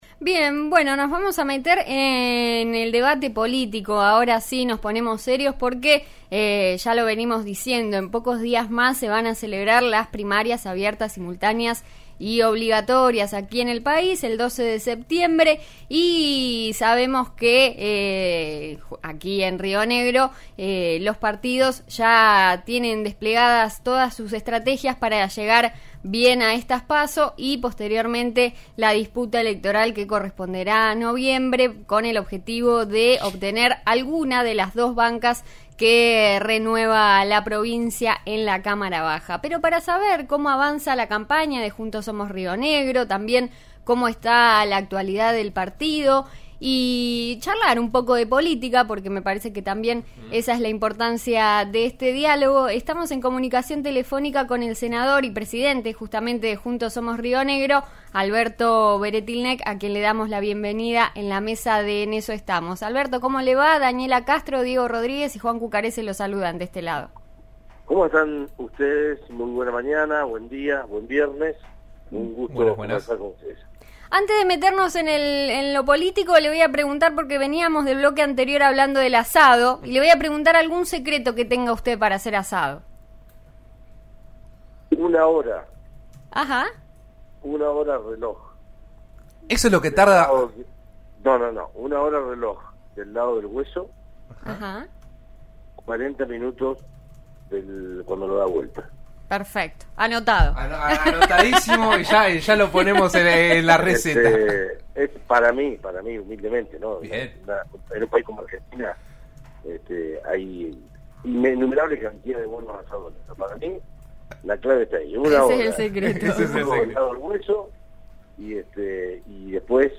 El senador rionegrino Alberto Weretilneck dialogó esta mañana con En Eso Estamos, el programa de RN Radio. En ese contexto se refirió a las próximas elecciones primarias, desmintió cortocircuitos con la actual gobernadora Arabela Carreras y se expresó respectos a distintos temas de actualidad.